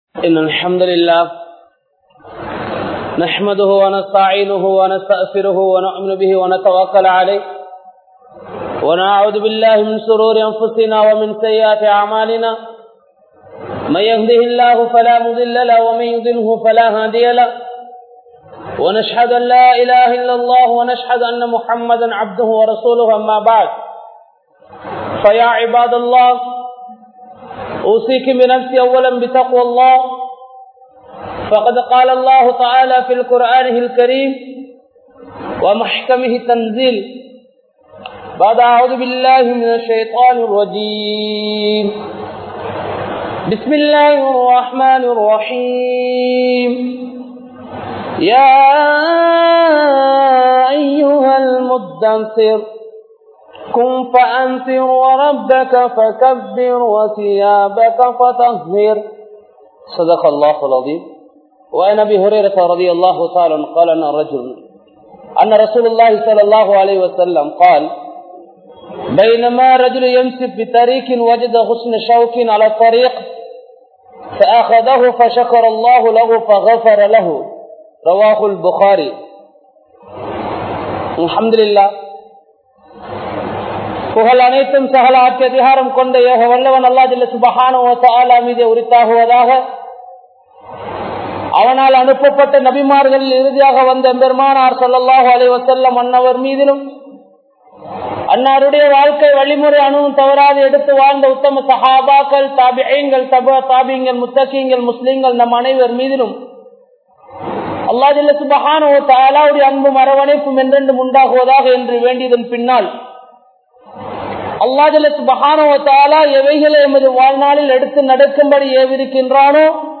Thooimaiyai Arimuham Seitha ISLAM (தூய்மையை அறிமுகம் செய்த இஸ்லாம்) | Audio Bayans | All Ceylon Muslim Youth Community | Addalaichenai